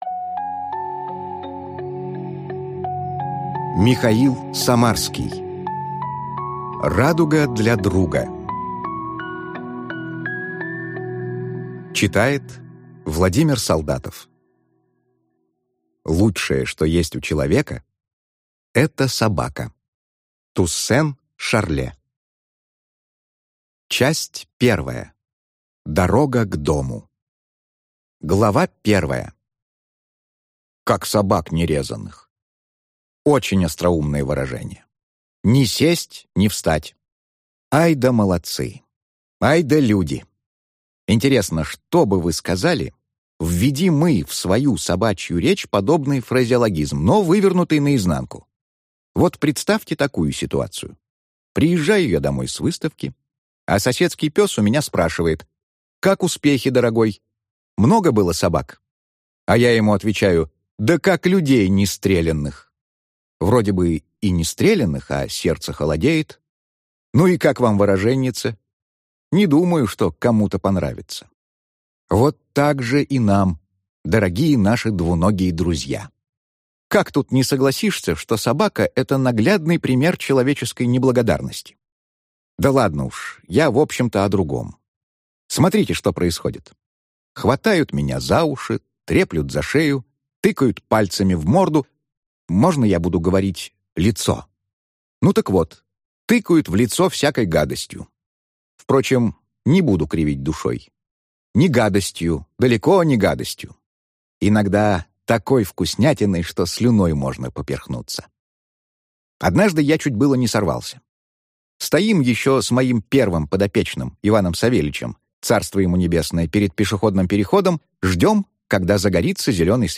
Аудиокнига Радуга для друга | Библиотека аудиокниг
Прослушать и бесплатно скачать фрагмент аудиокниги